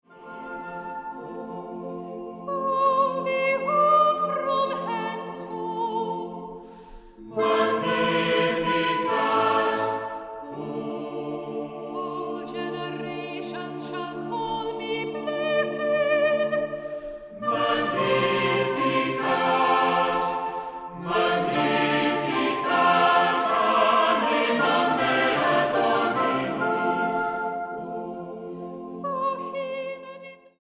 • Sachgebiet: Klassik: Geistliche Chormusik